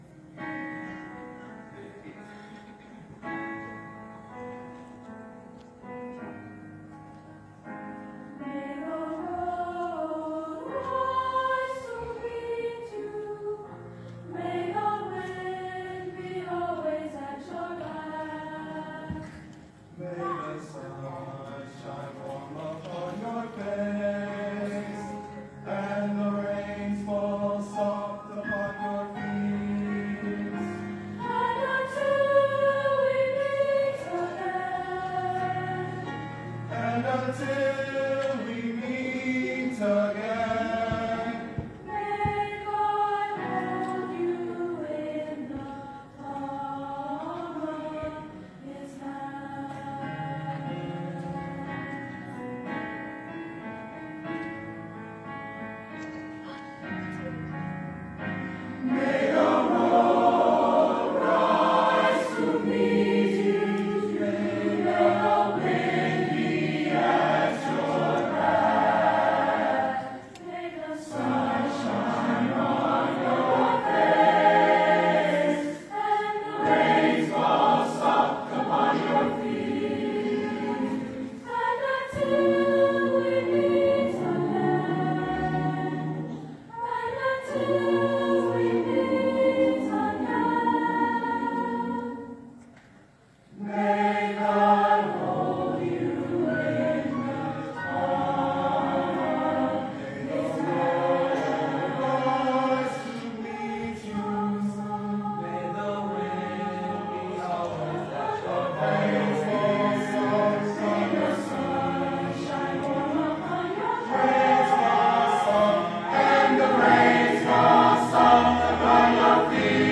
Voicing: SATB Choir